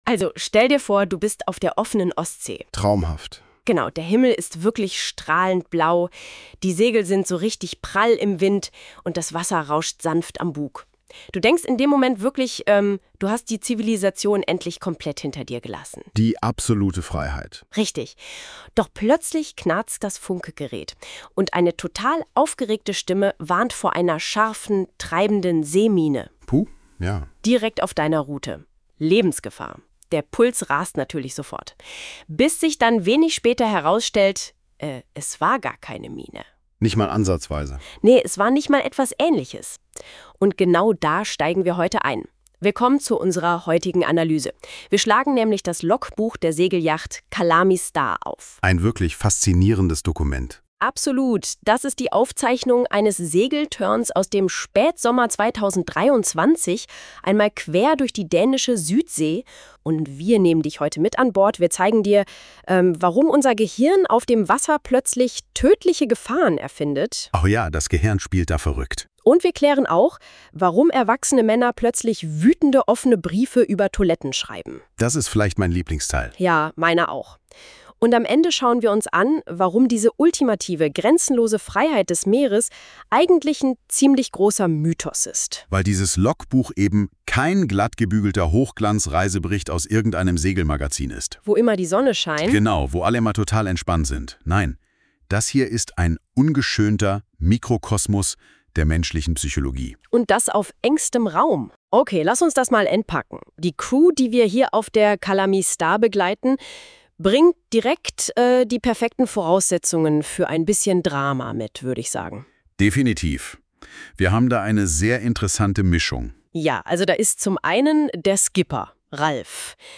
Das Logbuch und ein von NotebookLM erzeugter Podcast